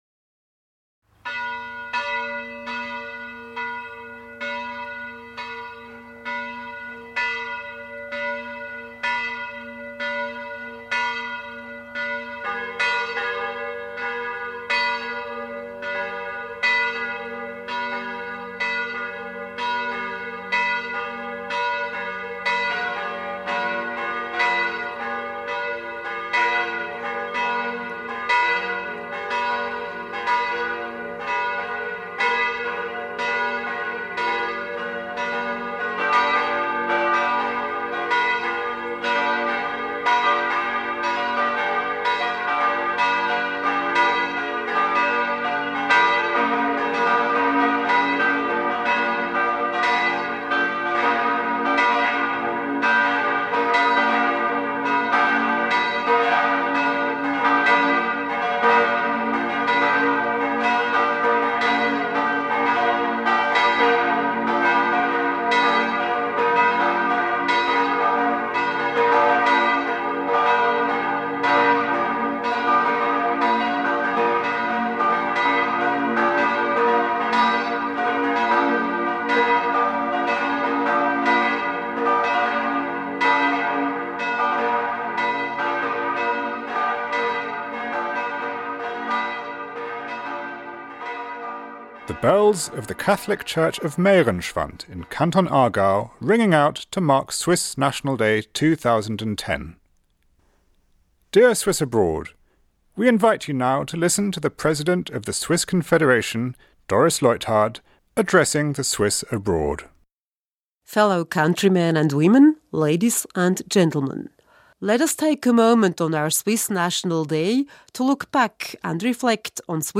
President Doris Leuthard's speech to the Swiss Abroad for Swiss National Day.